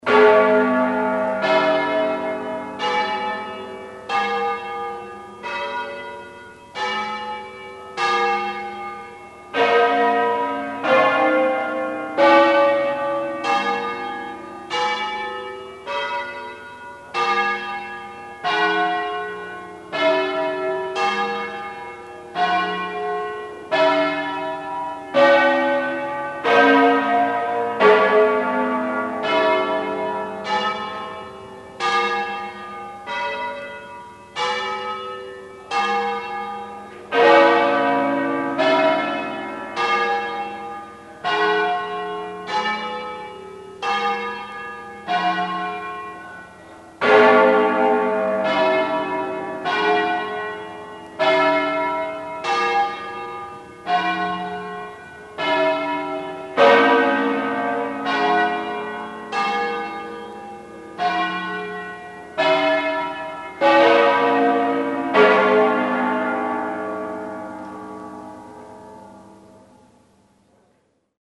In the tall (79.4m) tower, which was built between 1913 and 1925, is a ring of nine bells, tenor 90cwt (in Ab), making them the heaviest full circle ring in the world.
For a 800kb MP3 recording of a bit more of a concerto (with the 2003 tenor), click